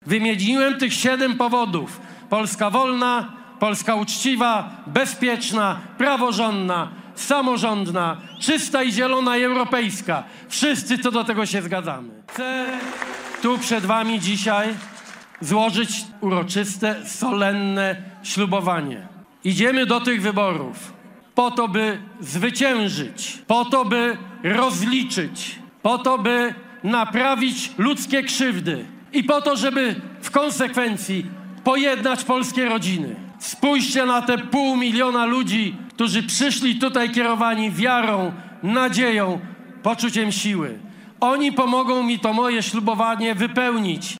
Ulicami Warszawy przeszedł „Marsz 4 czerwca”, zorganizowany przez opozycję. Punktem kulminacyjnym było przemówienie przewodniczącego Platformy Obywatelskiej Donalda Tuska na placu Zamkowym.